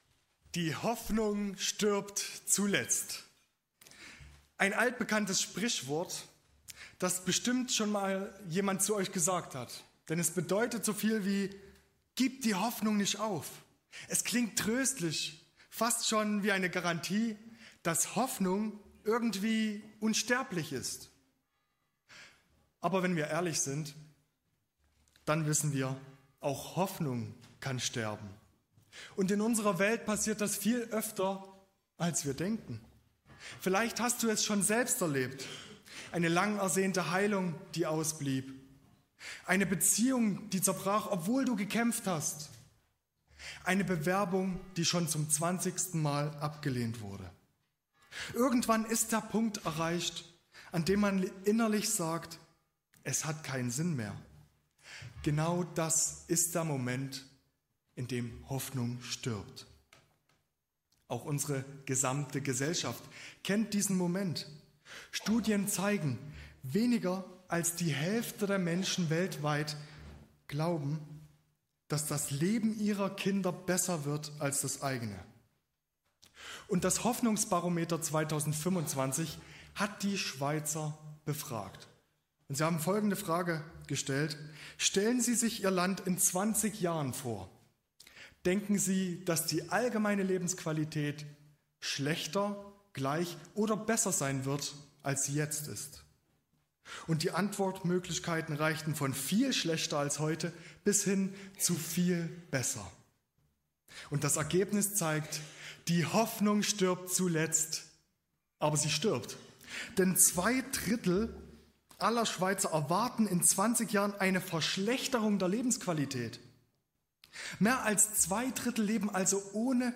In dieser Predigt geht es darum, worauf wir unsere Hoffnung wirklich bauen können, warum sie standhält – auch wenn wir sie noch nicht sehen – und wie wir hoffnungsvoll bleiben, selbst in Zeiten der Dunkelheit.